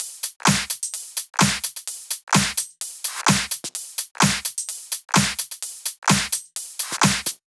VDE1 128BPM Full Effect Drums 2.wav